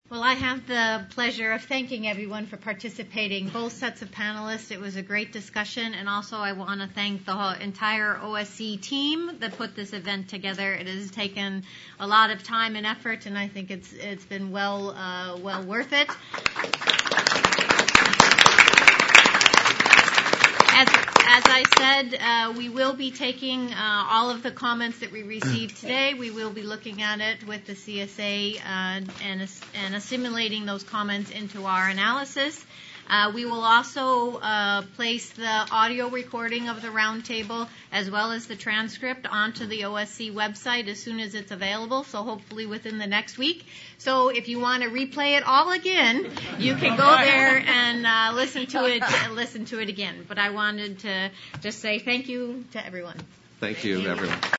CSA Roundtable on Proposed Best Interest and Targeted Reforms